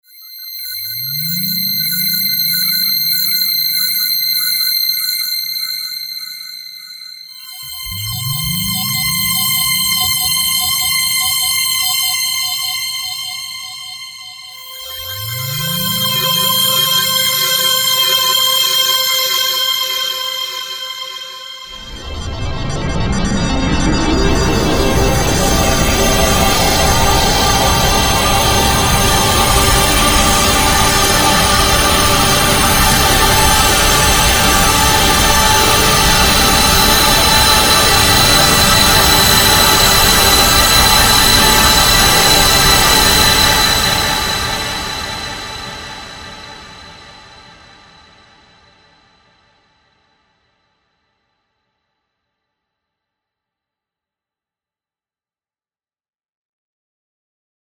今回作成したパッド音色のデモサウンドです。作成した音色は、
幅広い音域において単音でも和音でも演奏して使える音色を意図して
今回作成している音色においては、オシレータ1、2、4はフィルター 1の設定、オシレータ3はフィルター 2の設定で変化するように振り分けていますが、これは前述で紹介している疑似的に2音色を重ねたような音色にするためです。